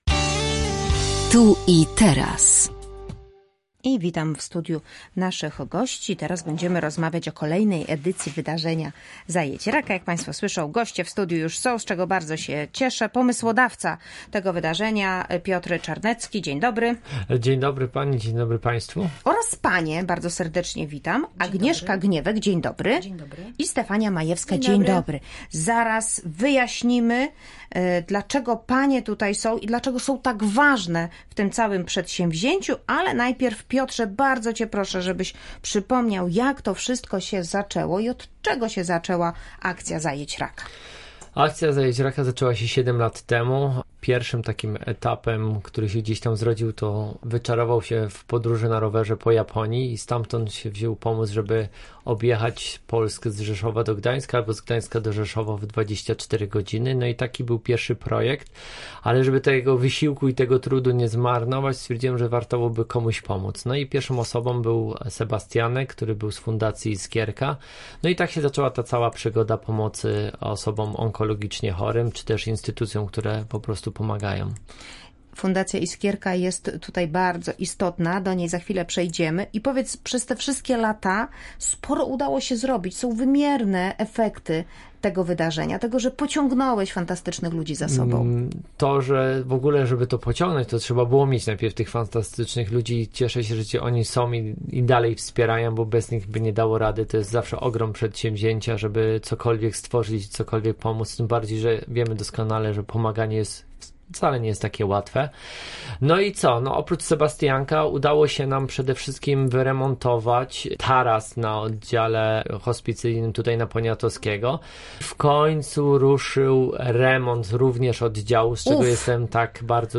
Z naszymi gośćmi rozmawiała